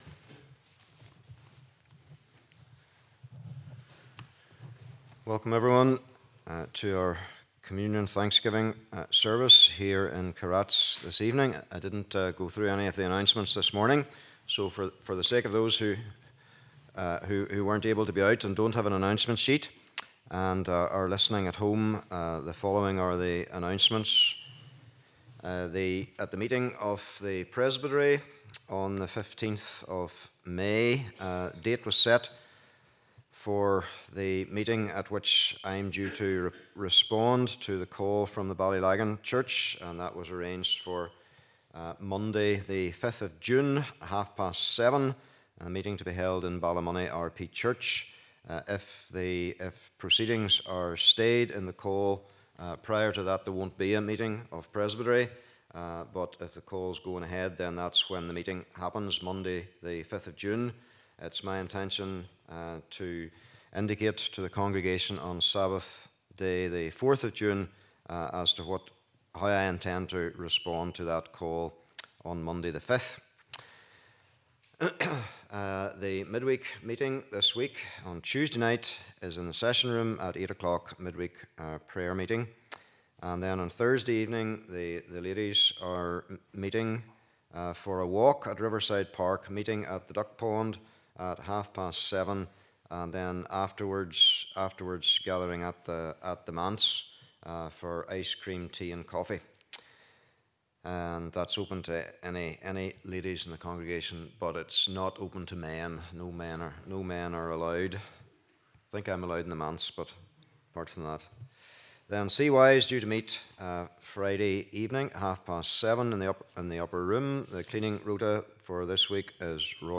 Communion Series May 2023 Passage: Isaiah 53 : 10 & 11 Service Type: Evening Service « The Suffering Servant